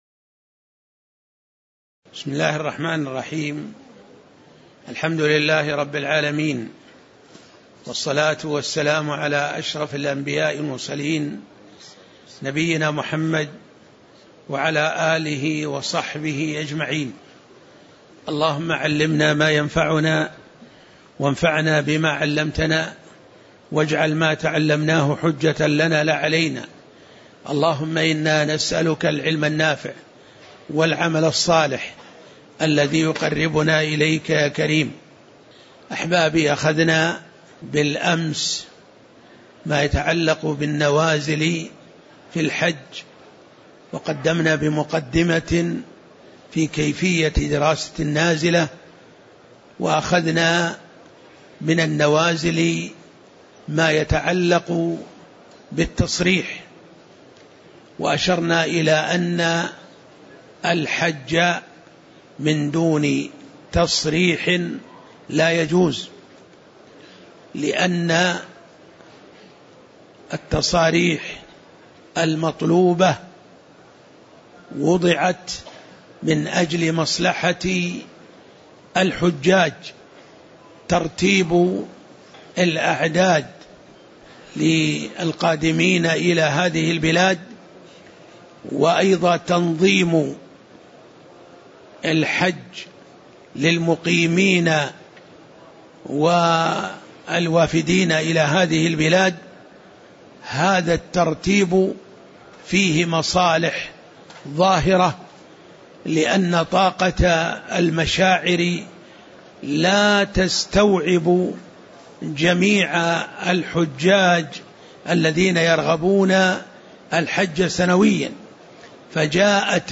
تاريخ النشر ٢٦ ذو القعدة ١٤٣٩ هـ المكان: المسجد النبوي الشيخ